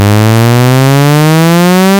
2秒で3000rpmから6000rpmへと。
こんな音になった。チープだが悪くない。つうか実際、黎明期のレースゲームの走行音って、まさにこんな音だよね。